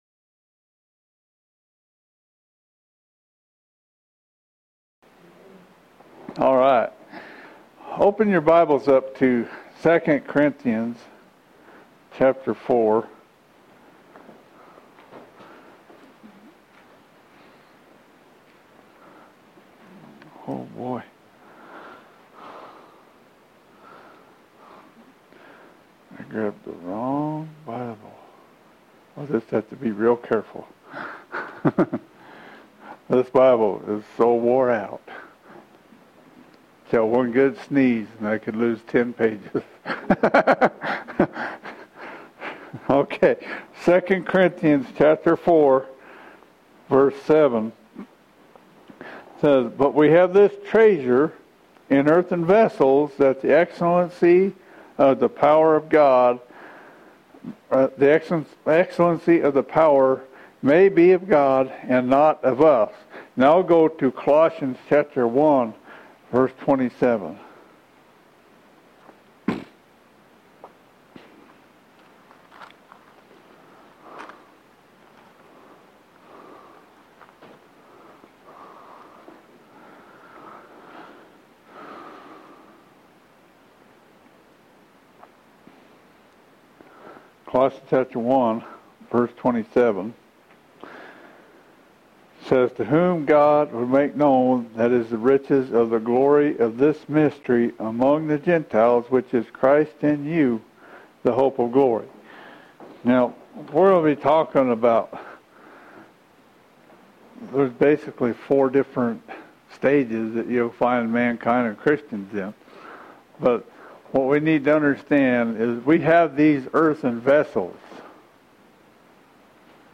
Recorded Sermons
Sunday Sermons